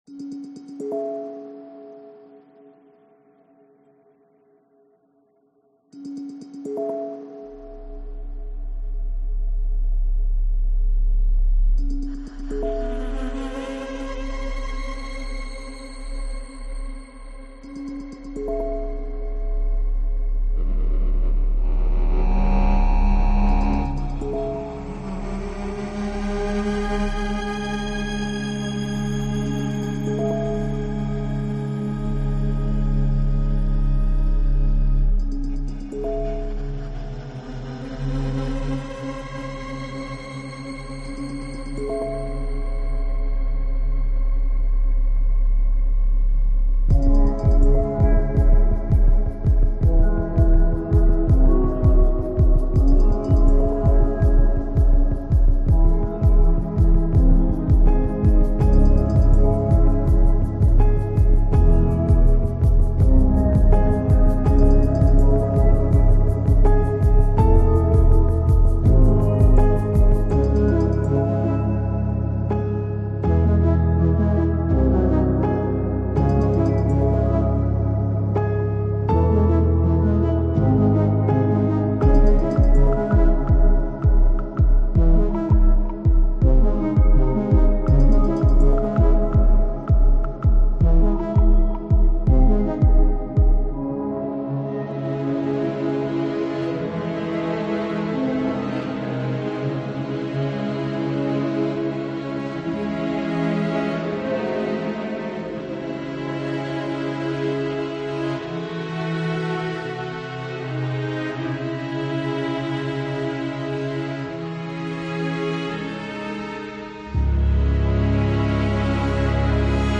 piano, strings, and ambient elements